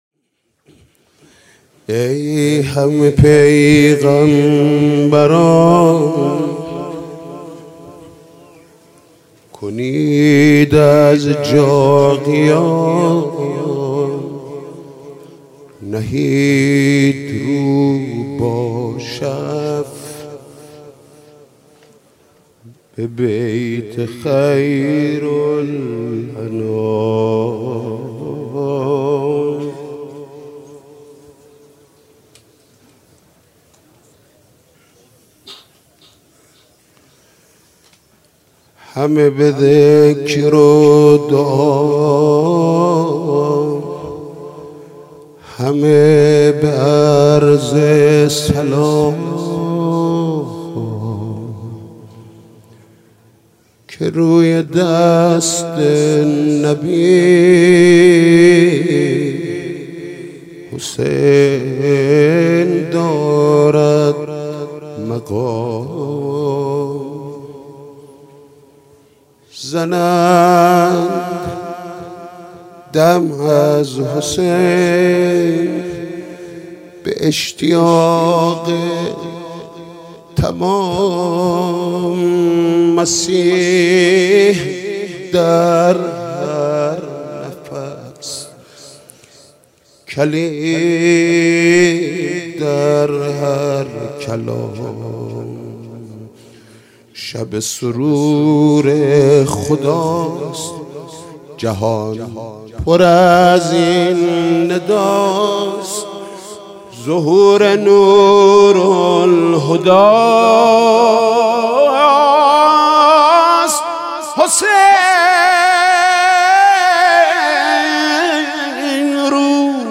مدح: ای همه پیغمبران کنید از جا قیام